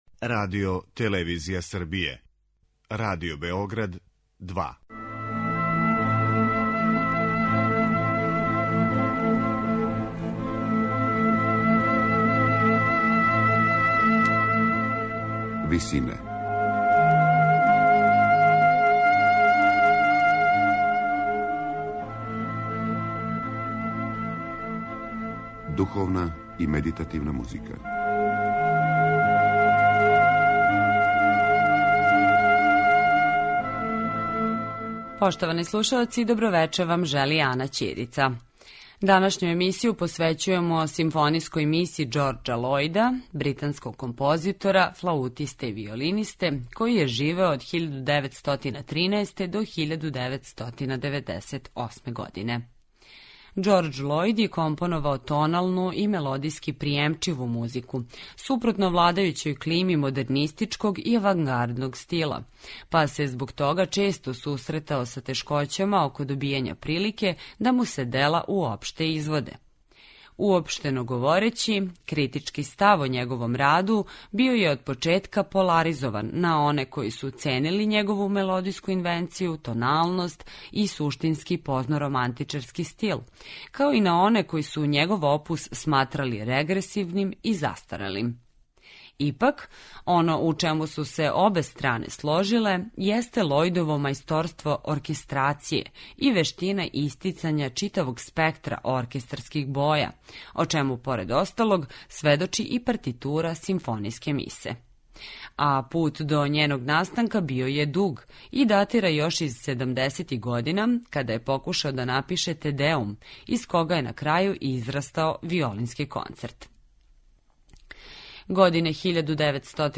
тоналну и мелодијски пријемчиву музику
суштински позноромантичарски стил